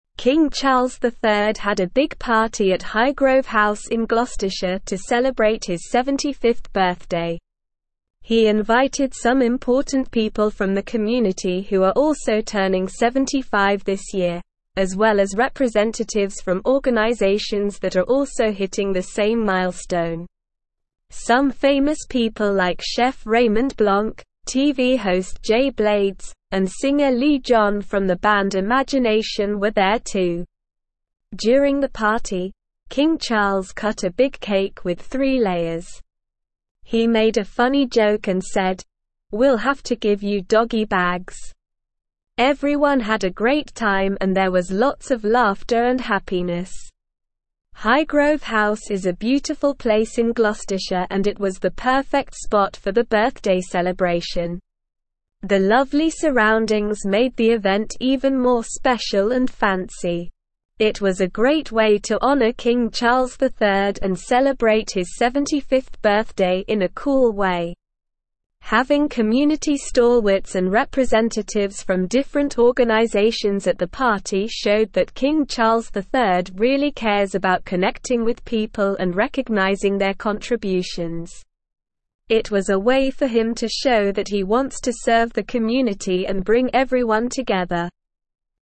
Slow
English-Newsroom-Upper-Intermediate-SLOW-Reading-King-Charles-III-Celebrates-75th-Birthday-with-Joyful-Party.mp3